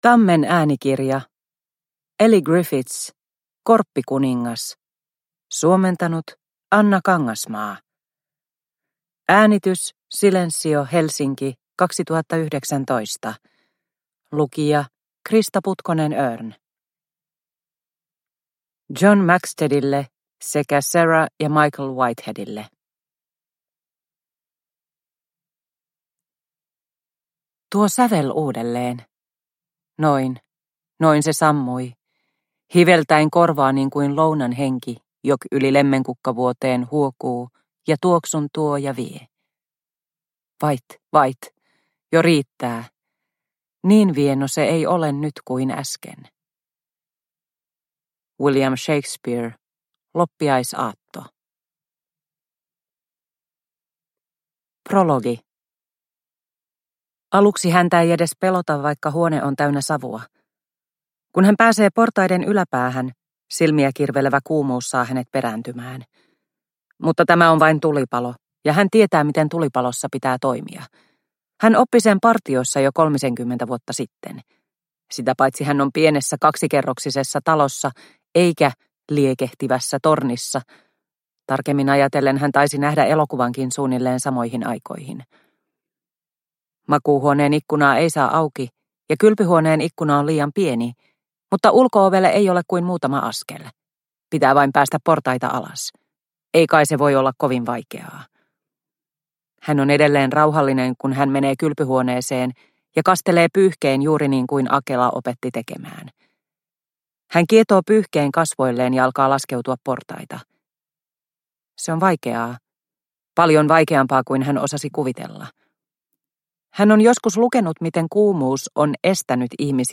Korppikuningas – Ljudbok – Laddas ner